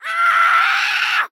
Hello Neighbor Scream
hn-scream-2.mp3